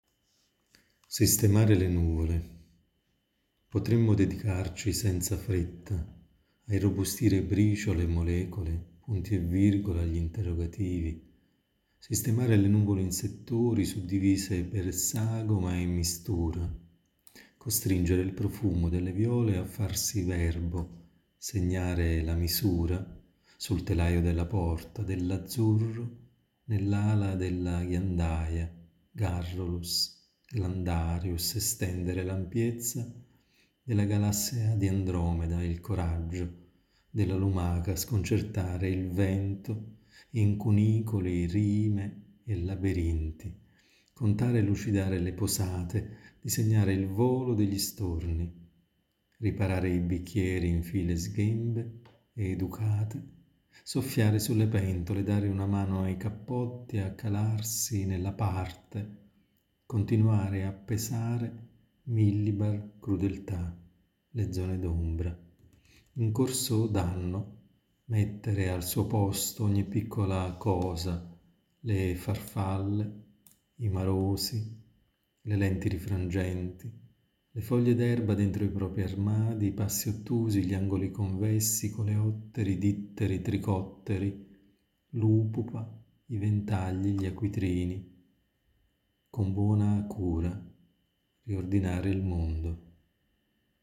Alla fine del testo una mia lettura dei versi.